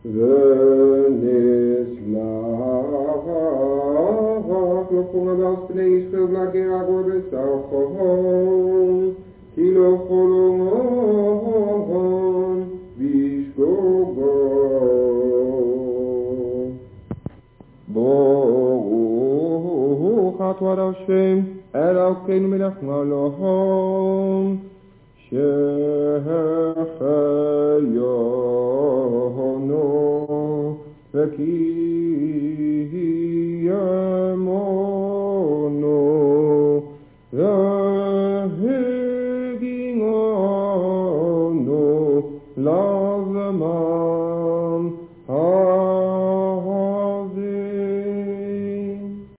op cassettebandjes.